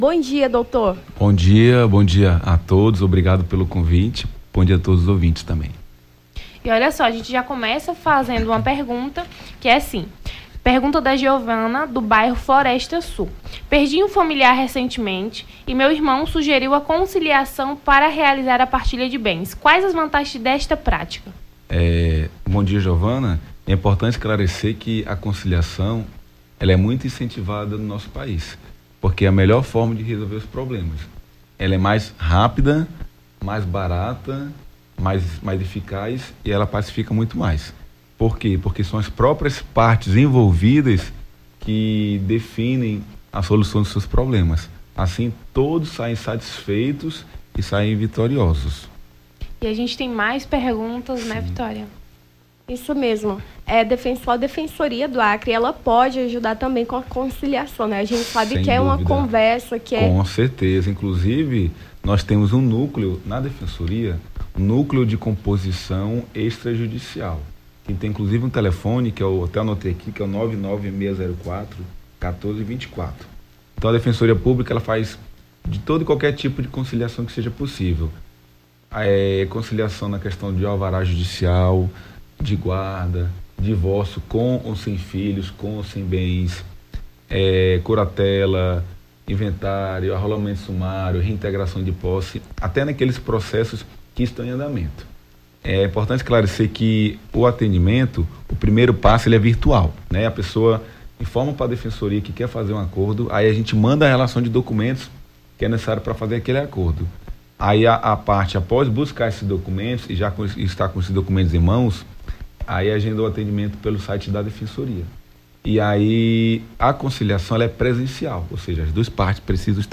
Seus direitos: Defensor público esclarece dúvidas sobre conciliação judicial